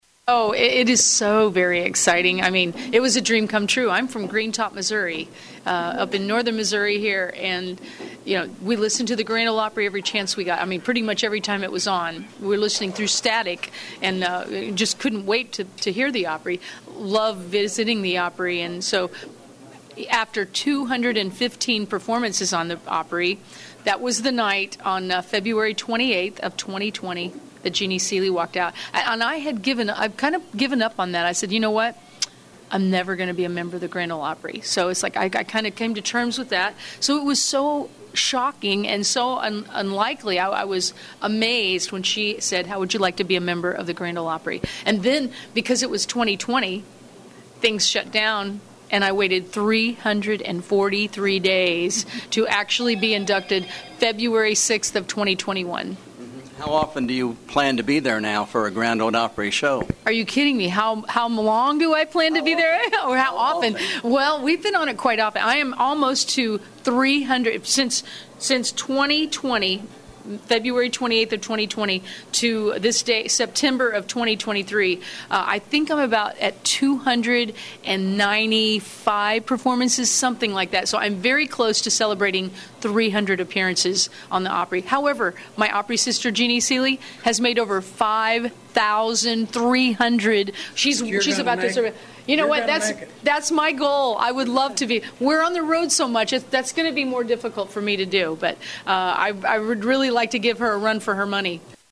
When bluegrass and country music performer Rhonda Vincent was in Trenton on Thursday, she was interviewed by KTTN prior to a “meet and greet” at HyVee. Vincent became a member of the Grand Ole Opry in February 2021, nearly one year after being invited to join.